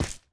resin_place2.ogg